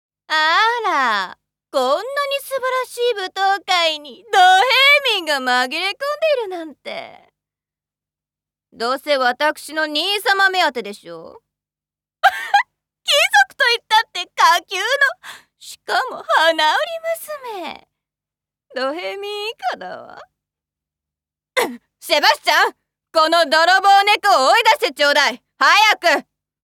ボイスサンプル
悪役令嬢